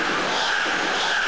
CHANT4L.mp3